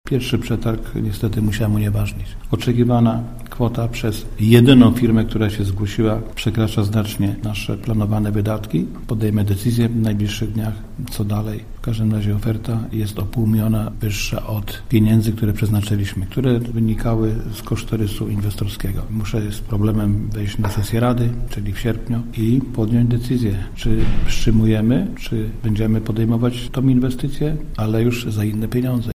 – Niestety, mimo, że otrzymaliśmy dofinansowanie, nie wiemy, czy remont uda się przeprowadzić – martwi się Ignacy Odważny, burmistrz Sulechowa.